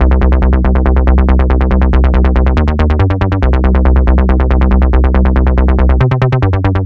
Бас сэмпл (техно, транс): Electro Bass
Sound_11002_ElectroBass.ogg